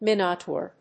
音節Min・o・taur 発音記号・読み方
/mínət`ɔɚ(米国英語), mάɪnət`ɔː(英国英語)/